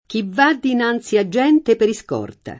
scorta [Sk0rta] s. f. — es. con acc. scr.: la felicità eterna a cui il pontefice è scòrta [la feli©it# et$rna a kk2i il pont%fi©e H Sk0rta] (Carducci) — es. con i‑ prost.: Chi va dinanzi a gente per iscorta [